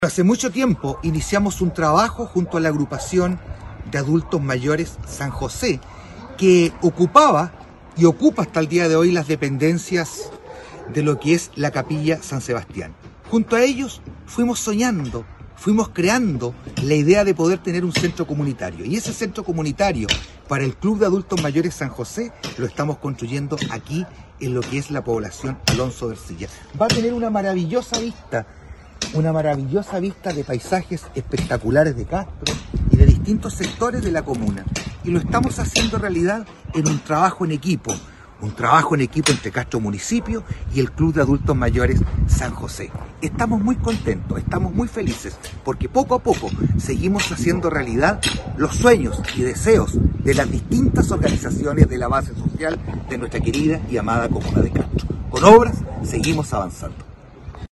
ALCALDE-VERA-CENTRO-COMUNITARIO.mp3